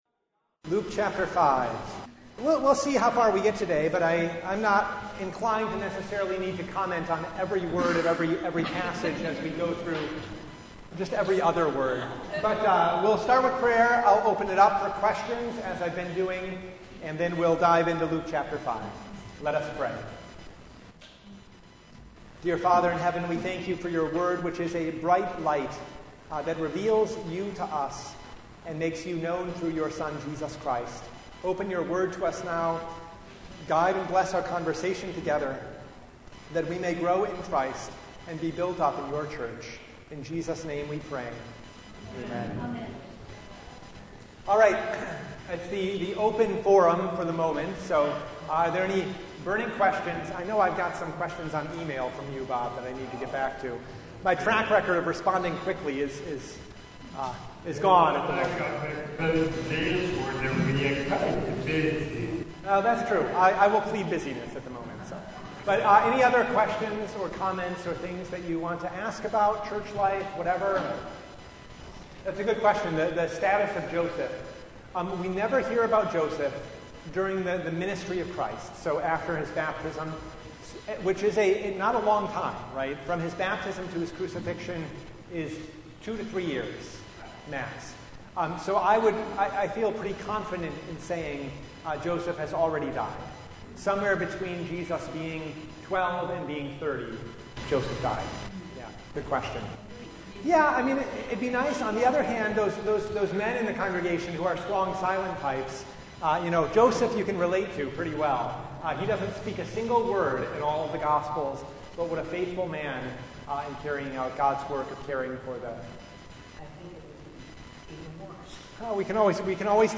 Passage: Luke 5:1-11 Service Type: Bible Study